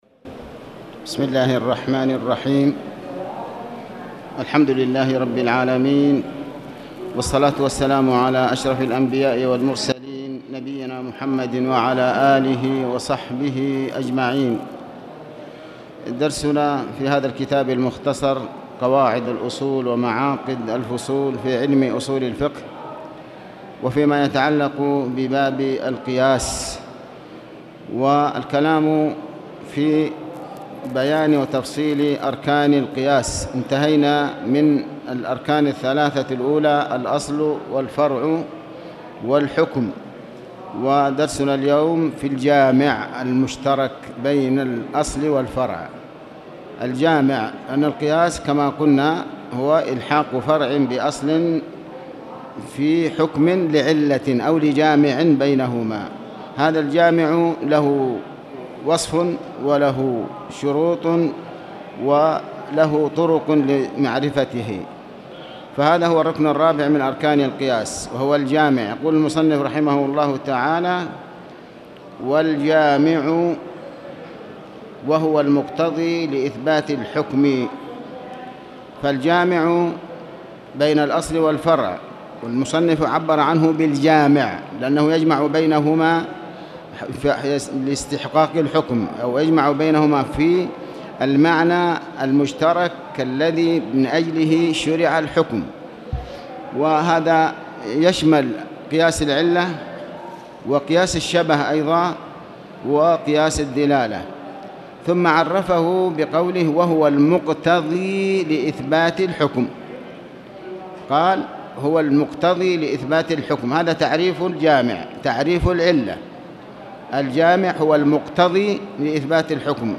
تاريخ النشر ٢ صفر ١٤٣٨ هـ المكان: المسجد الحرام الشيخ: علي بن عباس الحكمي علي بن عباس الحكمي القياس-الجامع المشترك بين الأصل والفرع